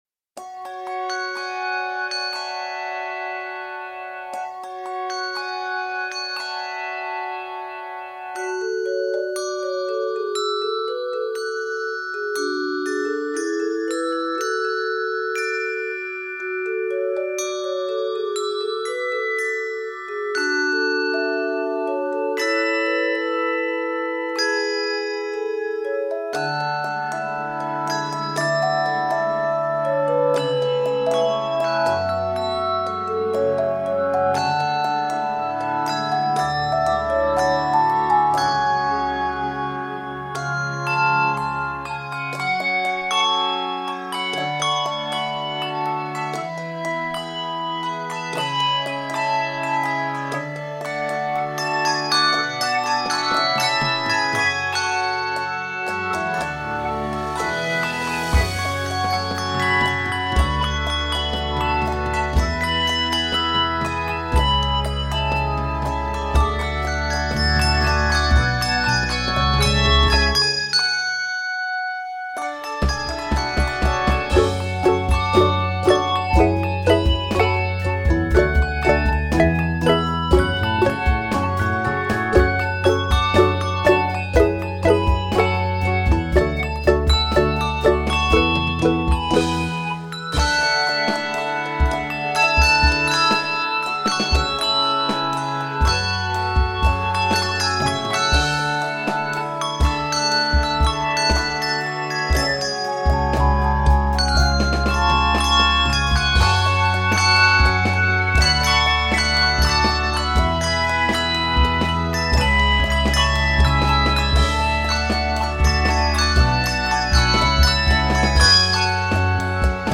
Octaves: 4-6